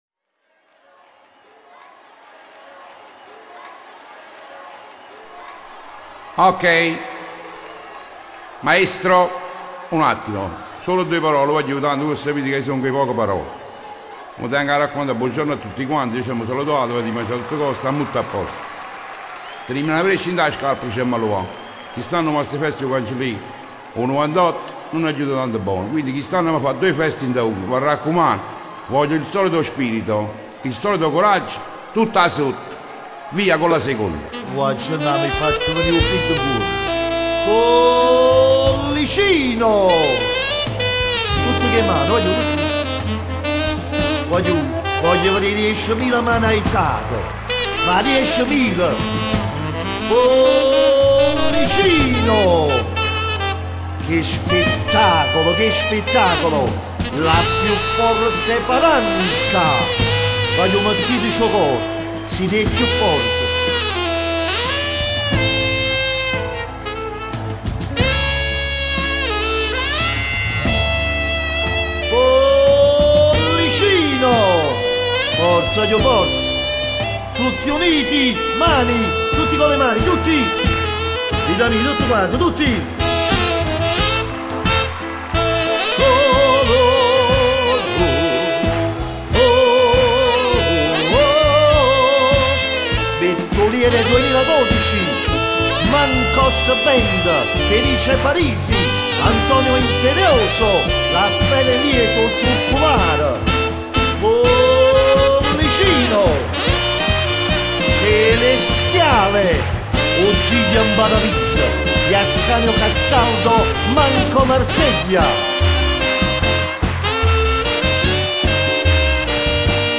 Medley Live16.mp3